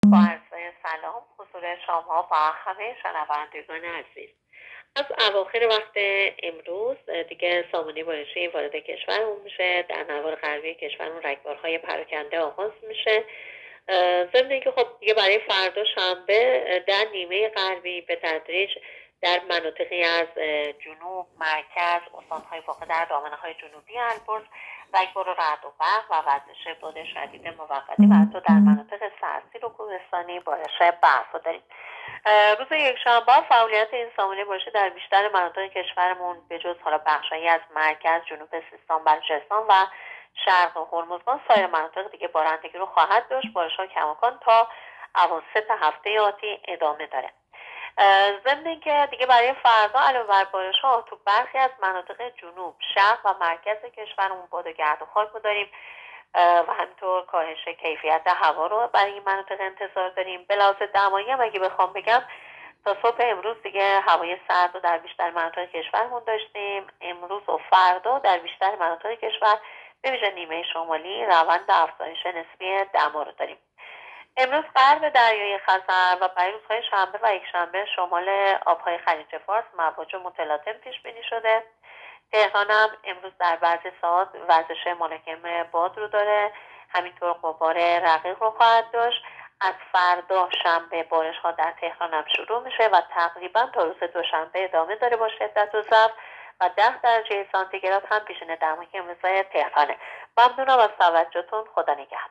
گزارش رادیو اینترنتی از آخرین وضعیت آب و هوای هفدهم بهمن؛